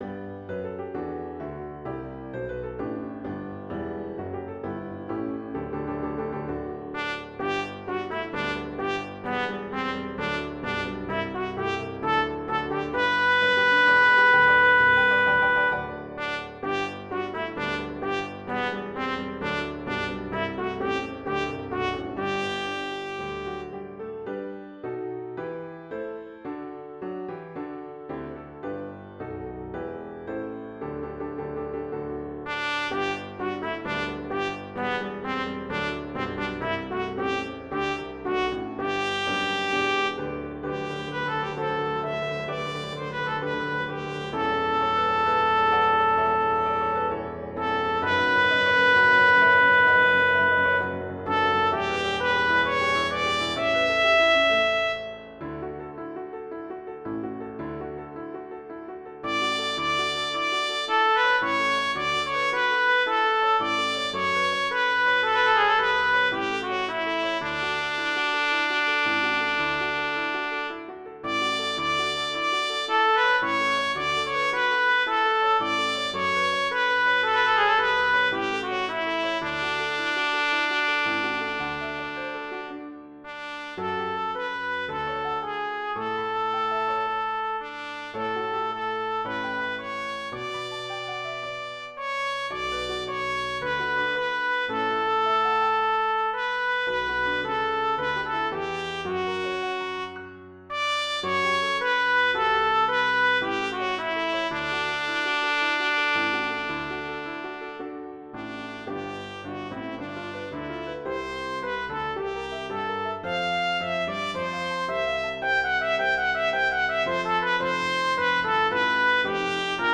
Intermediate Instrumental Solo with Piano Accompaniment.